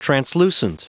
Prononciation du mot translucent en anglais (fichier audio)
Prononciation du mot : translucent